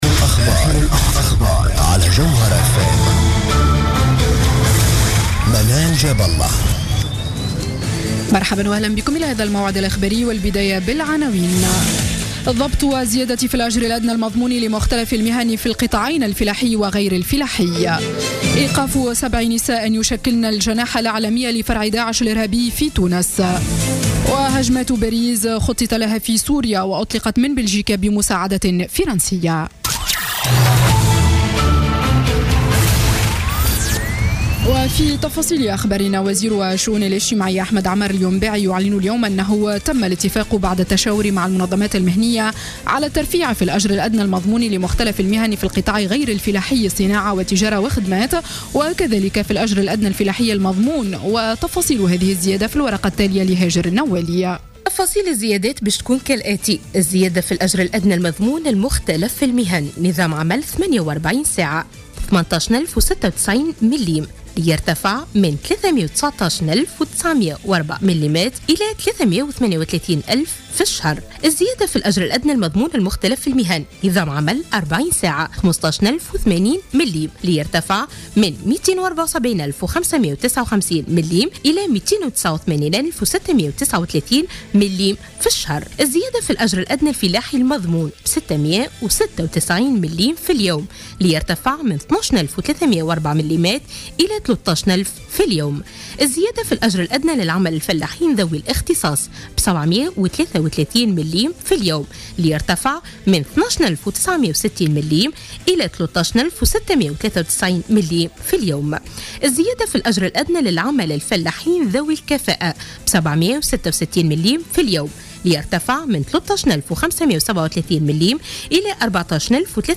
نشرة أخبار الساعة السابعة مساء ليوم الإثنين 16 نوفمبر 2015